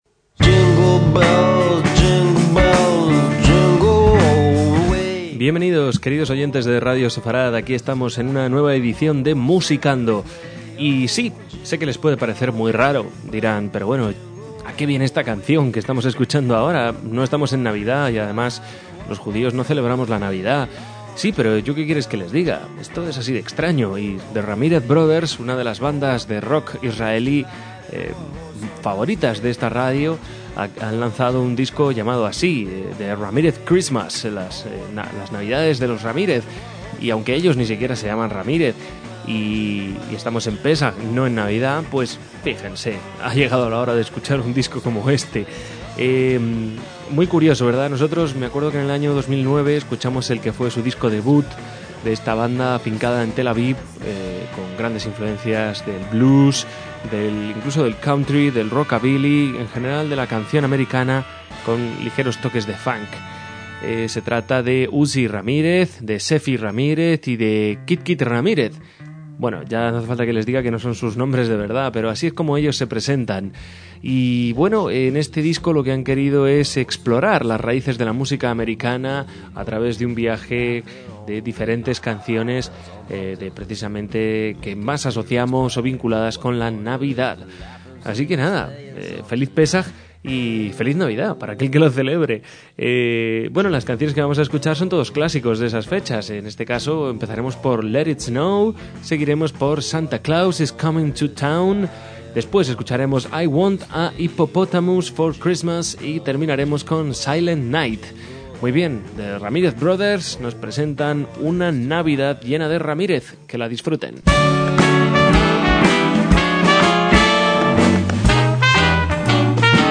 sino un trio de rock israelí, con una formación muy original de guitarra
que definen su estilo como rockabilly y blues a gran velocidad. Y a finales de 2010 se descolgaron con un disco de temas navideños versionados de forma bastante particular.